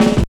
44 SNARE 2-R.wav